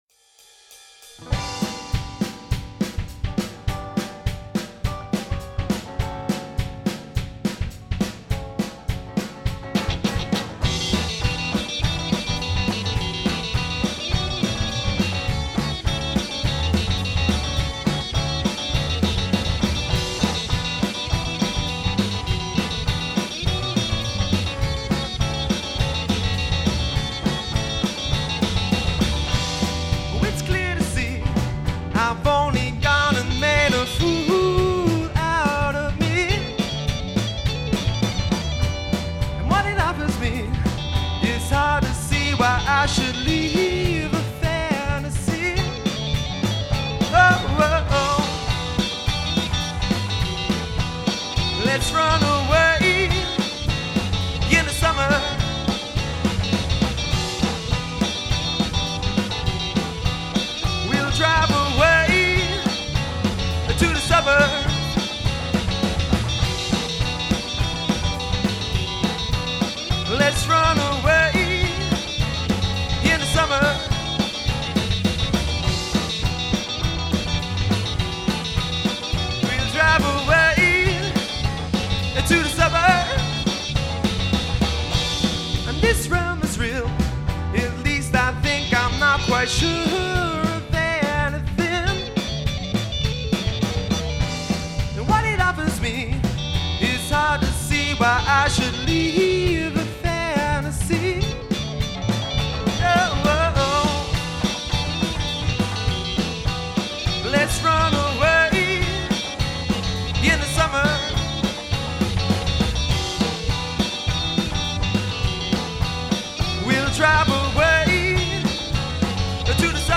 Each of the acts recorded live versions of their entries.
indie rock band